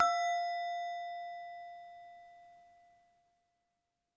bell chime ding dong goblet instrument ping sound effect free sound royalty free Music